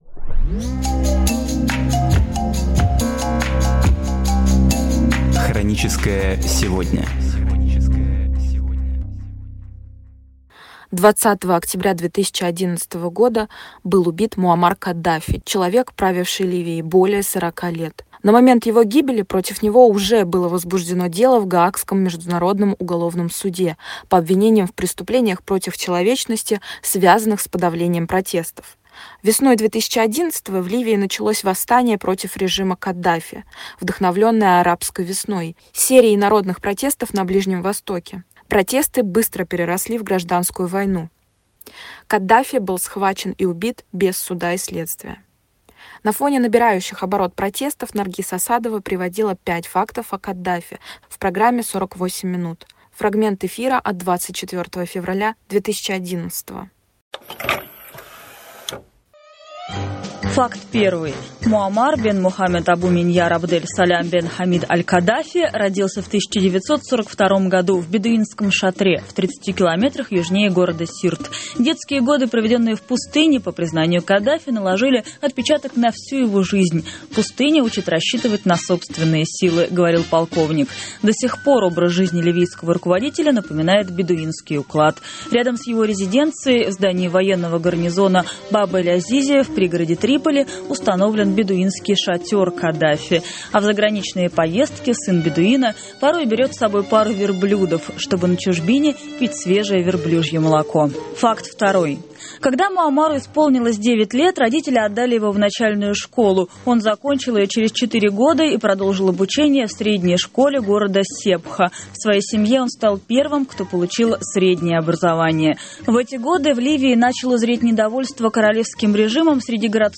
Программы из архива «Эха Москвы»